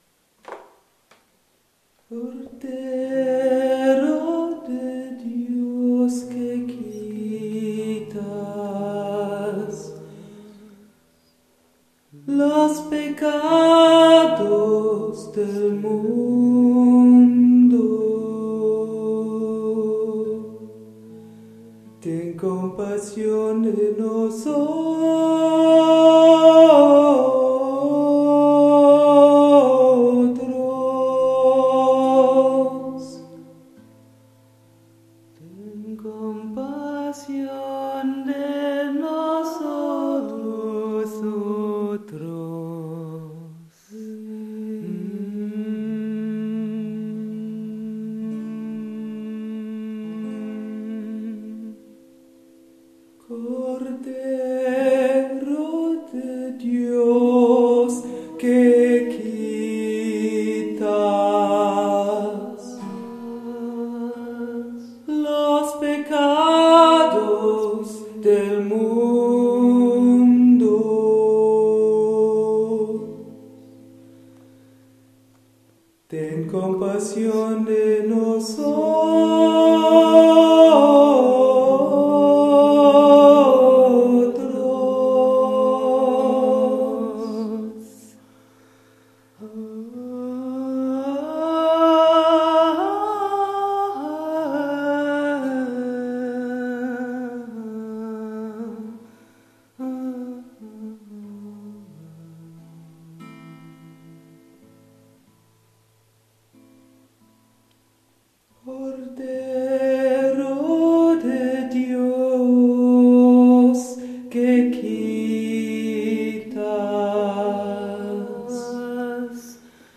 Ich habe am Ende von der Mondarbeit "Agnus Dei" aufgenommen. Die Musik wurde so - soweit ich weiß, von Ariel Ramirez für eine lateinamerikanische Weihnachtsmesse komponiert.